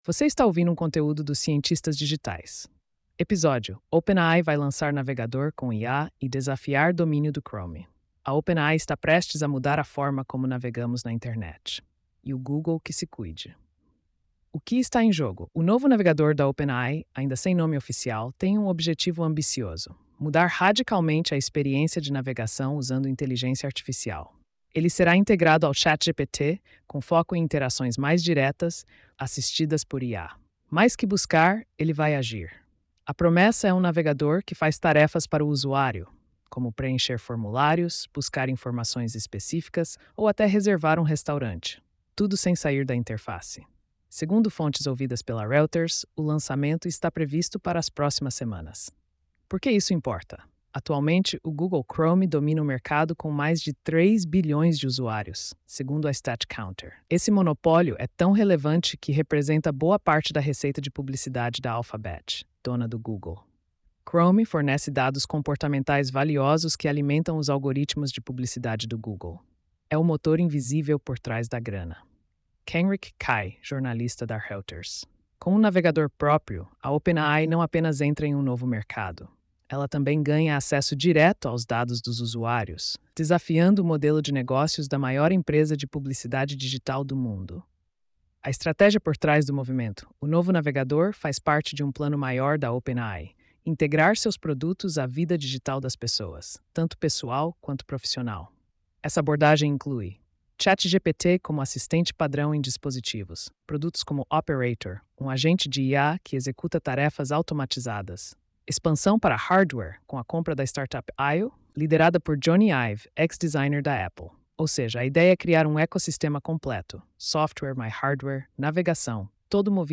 post-3313-tts.mp3